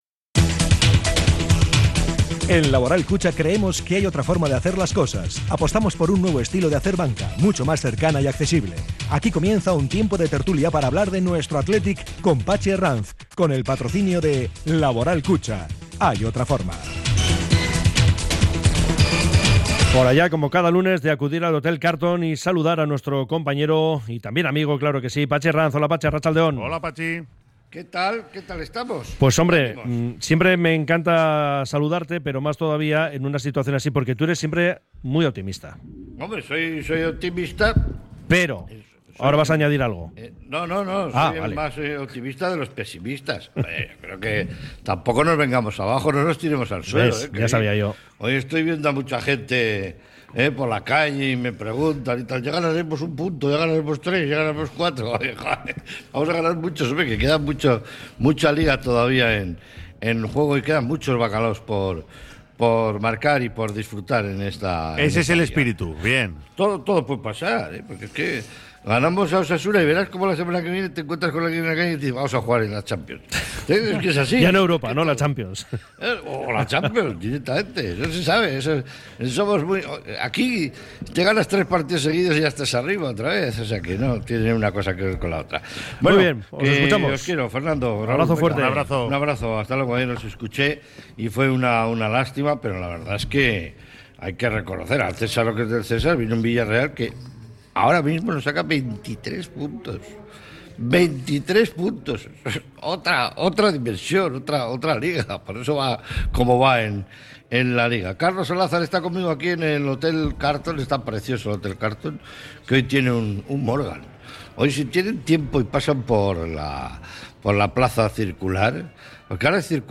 desde el hotel Carlton